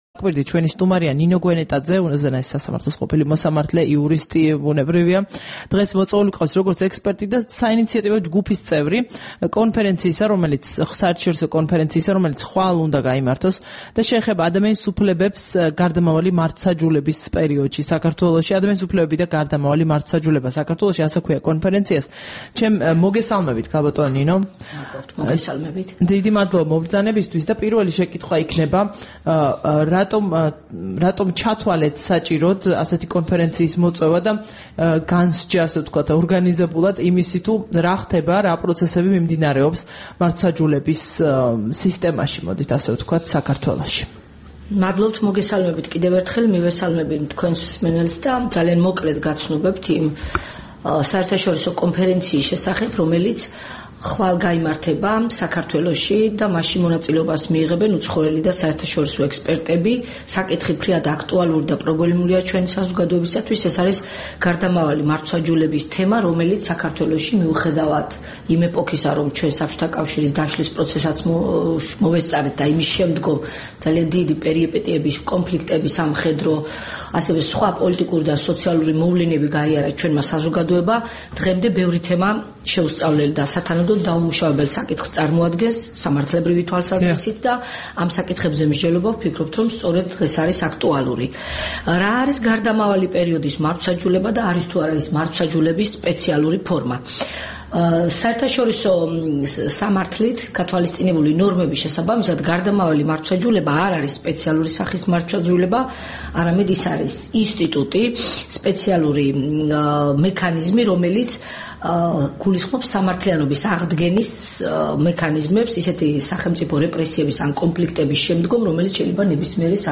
საუბარი ნინო გვენეტაძესთან